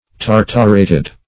tartarated - definition of tartarated - synonyms, pronunciation, spelling from Free Dictionary Search Result for " tartarated" : The Collaborative International Dictionary of English v.0.48: Tartarated \Tar"tar*a`ted\, a. (Chem.)